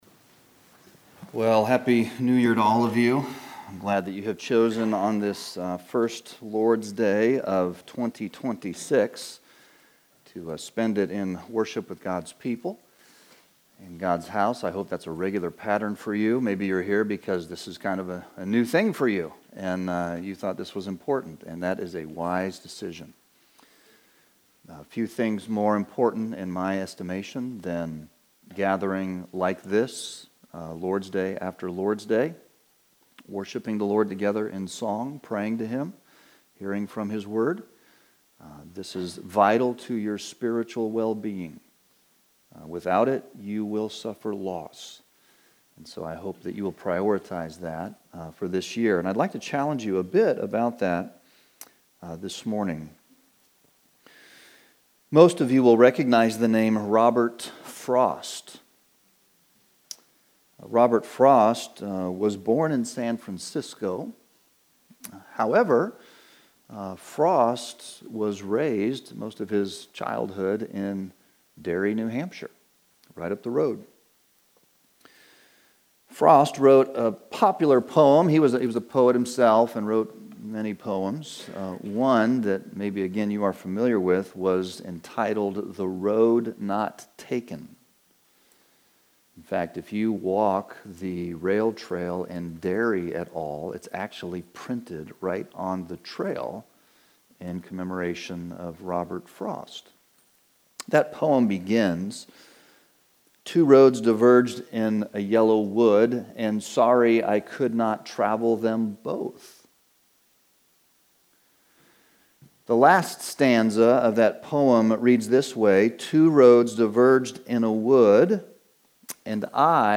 We trust you will be encouraged by the preaching and teaching ministry of Heritage Baptist Church in Windham, NH.